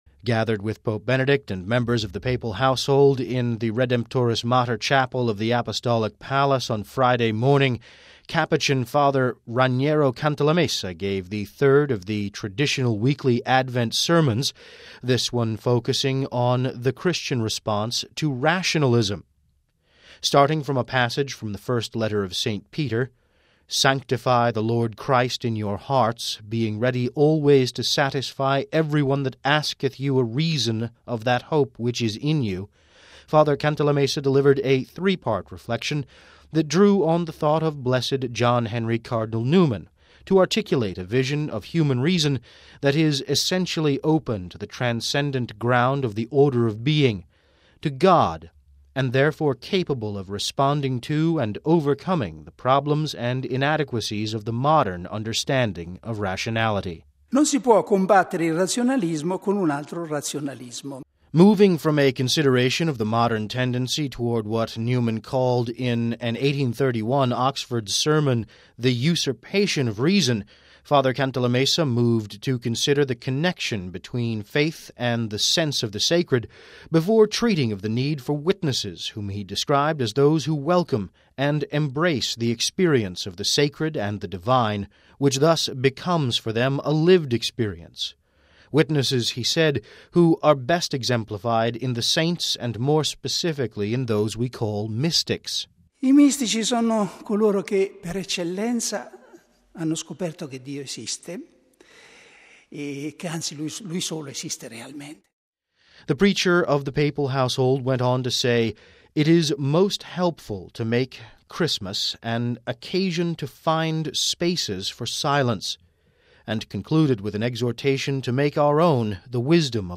Gathered with the Holy Father and other members of the papal household in the Redemptoris mater chapel of the Apostolic Palace on Friday morning, Capuchin Fr. Raniero Cantalamessa gave the third of the traditional weekly Advent sermons – this one focusing on the Christian response to rationalism.